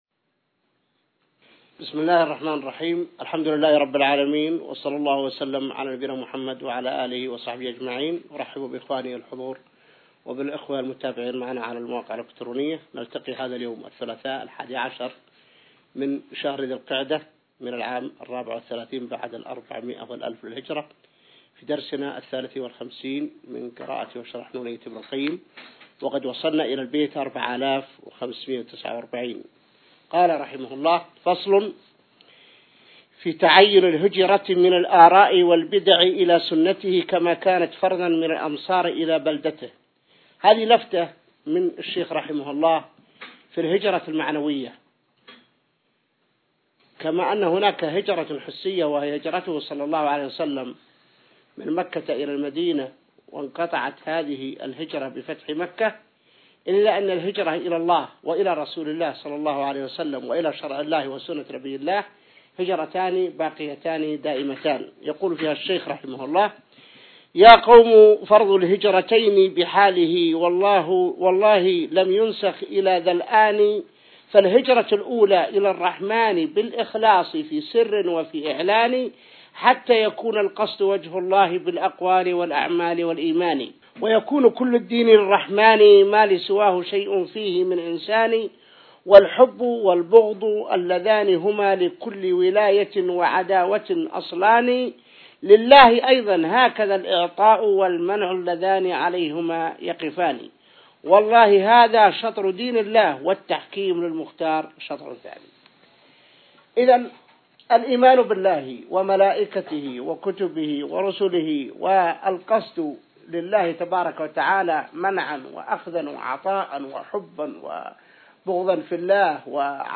الدرس 53 من شرح نونية ابن القيم | موقع المسلم